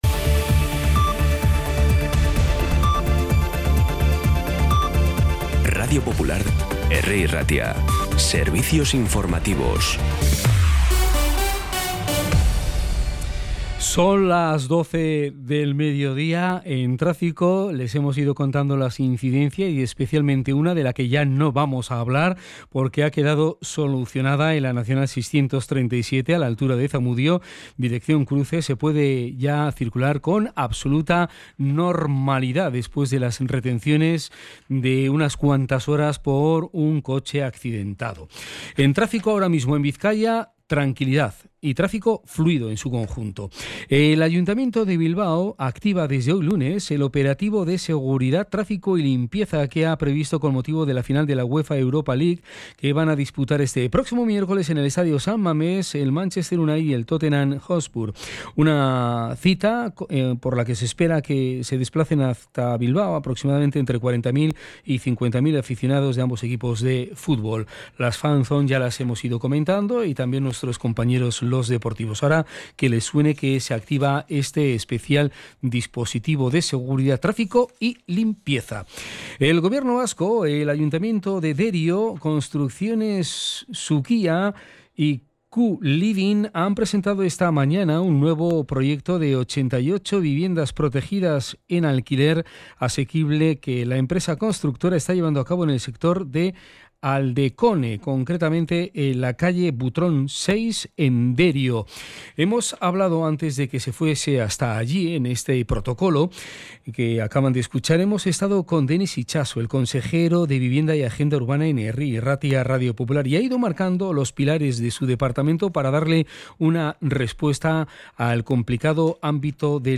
Las noticias de Bilbao y Bizkaia del 19 de mayo a las 12
Los titulares actualizados con las voces del día.